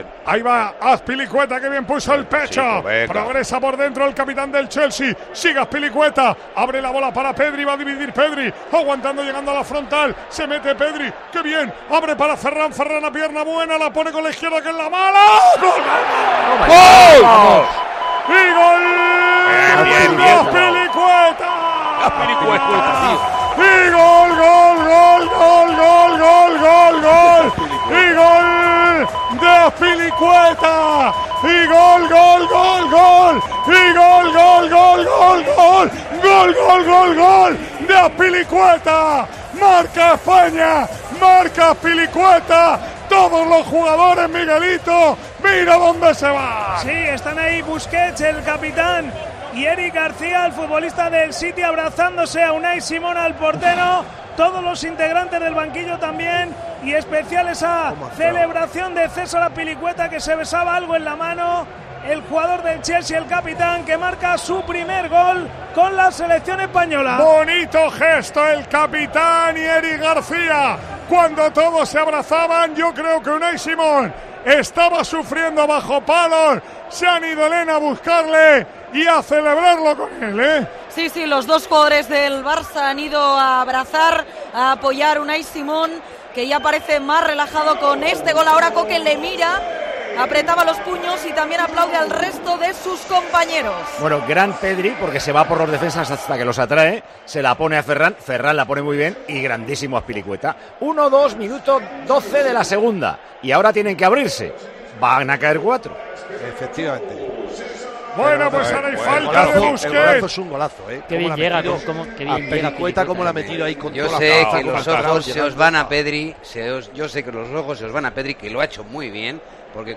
ASÍ NARRÓ MANOLO LAMA LOS GOLES DE CROACIA, 5 - ESPAÑA, 3